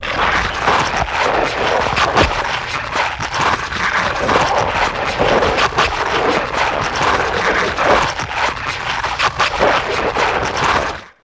Rubber Wet Suit Removal Loop